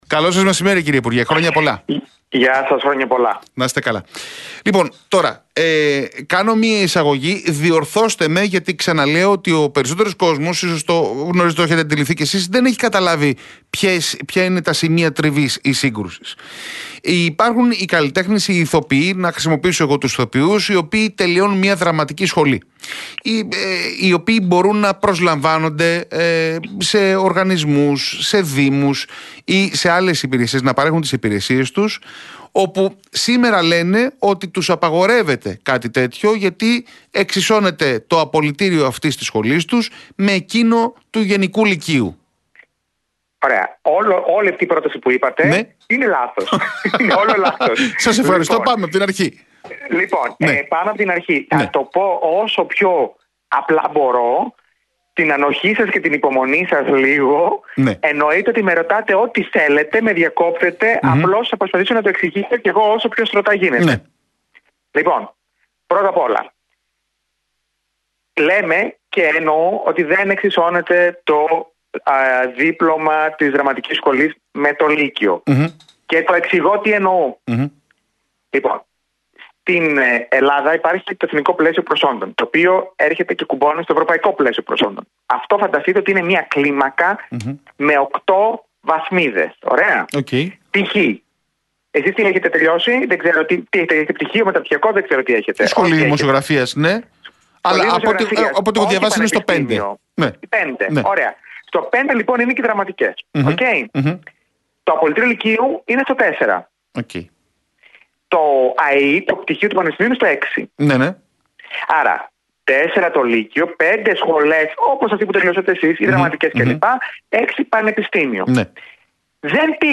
Διευκρινίσεις για το Προεδρικό Διάταγμα που αφορά στους καλλιτέχνες και την καλλιτεχνική εκπαίδευση μετά και τις αντιδράσεις ηθοποιών, μουσικών και σωματείων του κλάδου έδωσε ο υφυπουργός Πολιτισμού, Νίκος Γιατρομανωλάκης.